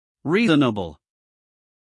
reasonable-ɤ-us-male.mp3